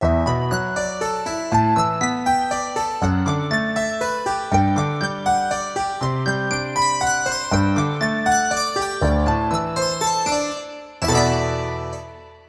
Sección instrumentos varios (orquesta sinfónica) Breve pieza ejemplo.
orquesta
Sonidos: Música